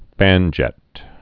(fănjĕt)